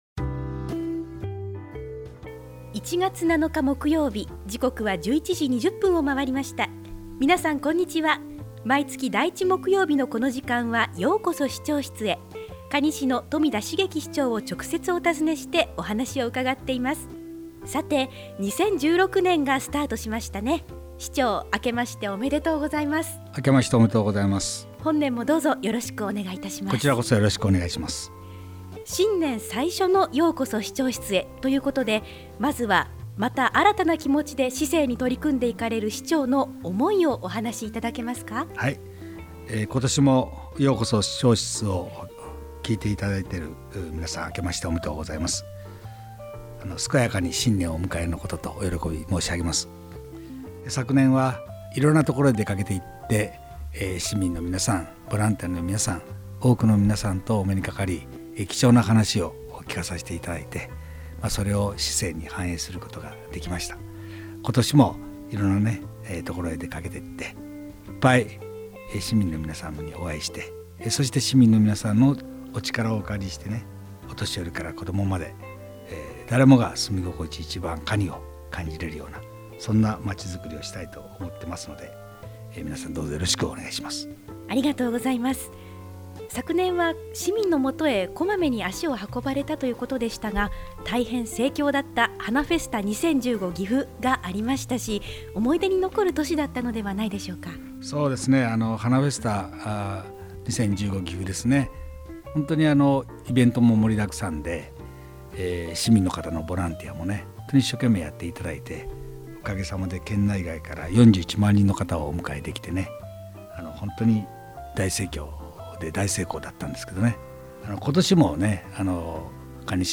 可児市長室へ直接伺って、まちづくりの課題、魅力ある地域、市政情報などを 中心に、新鮮な情報を可児市長自ら、皆様にお届けする番組です。
◎ 放送時間 毎月第１木曜日 １１：２０～ 可児市長 ： 冨田 成輝 ▼ 平成２８年１月 ７日 放送分 【今回のテーマ】 「可児市は今年も楽しみ 盛り沢山！」